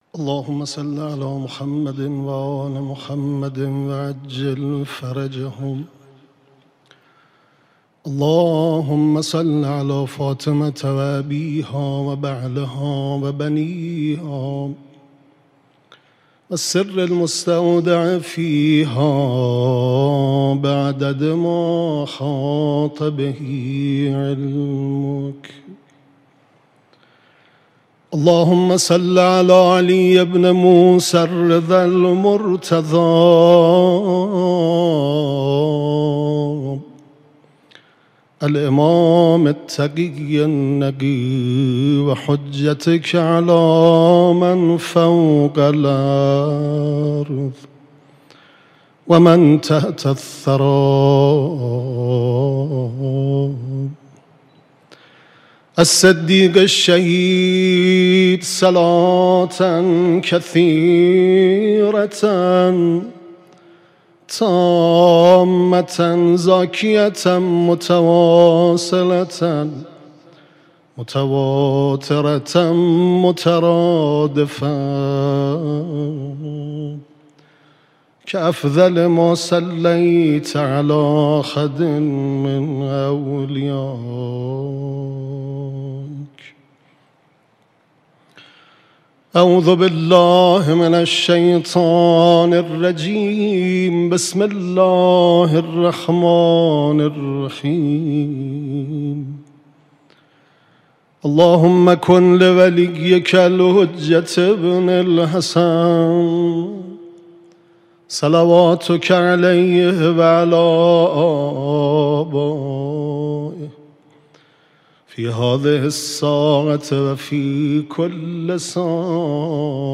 اولین شب عزاداری فاطمیه ۱۴۴۲ در حسینیه امام خمینی رحمه الله
❖❖❖مراسم ایام فاطمیه بیت رهبری❖❖❖